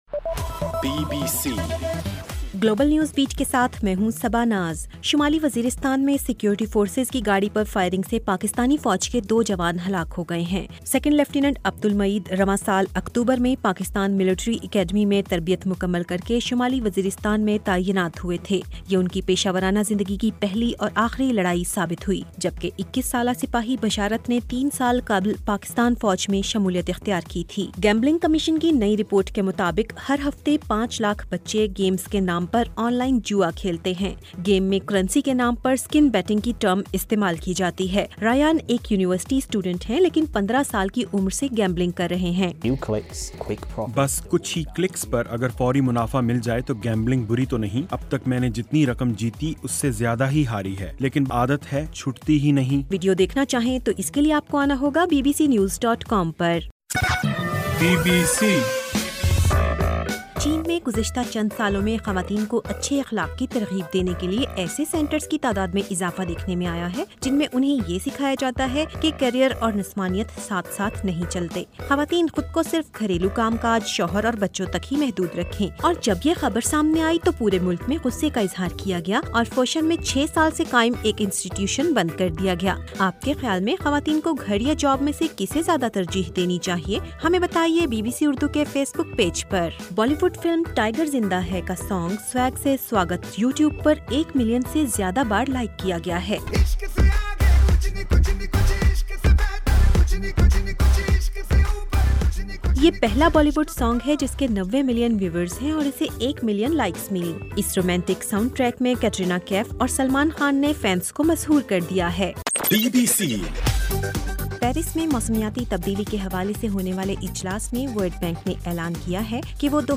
گلوبل نیوز بیٹ بُلیٹن اُردو زبان میں رات 8 بجے سے صبح 1 بجے تک ہر گھنٹےکے بعد اپنا اور آواز ایف ایم ریڈیو سٹیشن کے علاوہ ٹوئٹر، فیس بُک اور آڈیو بوم پر ضرور سنیے۔